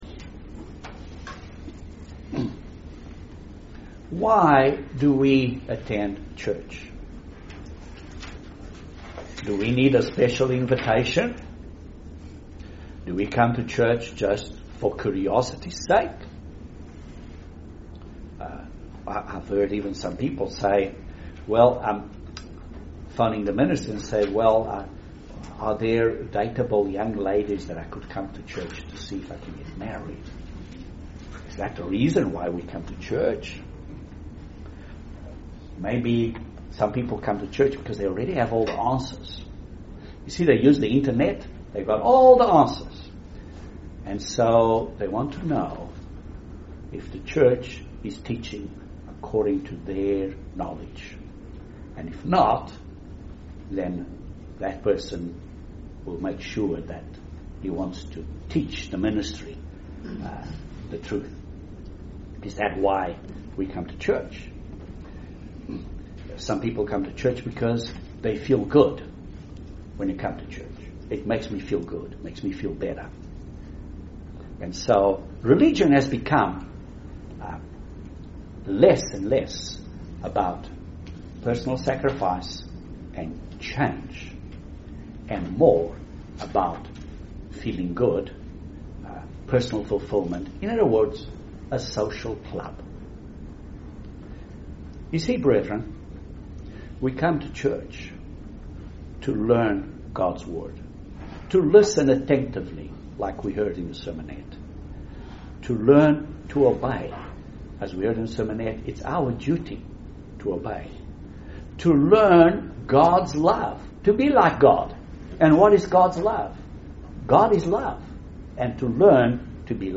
We are commanded to assemble and we have recalibrate ourselves daily. Watch and listen to this Sermon on why we should come to church.